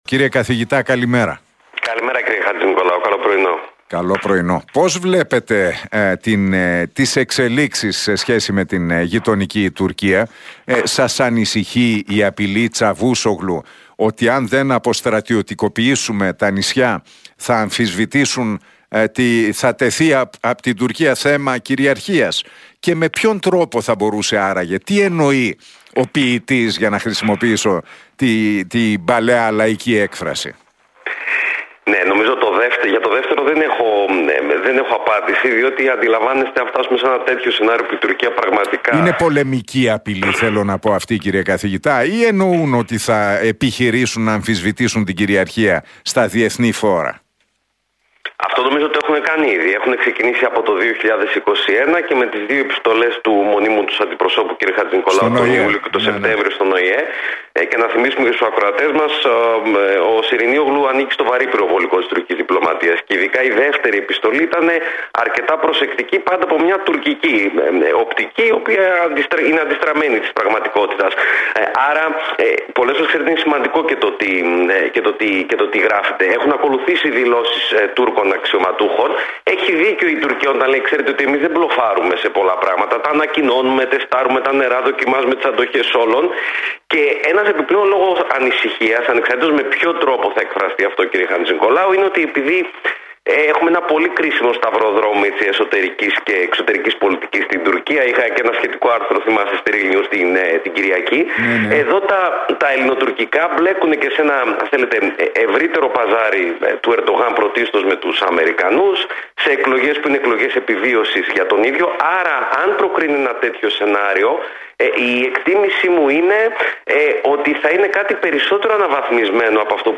σχολίασε εξελίξεις με την Τουρκία μιλώντας στην εκπομπή του Νίκου Χατζηνικολάου στον Realfm 97,8.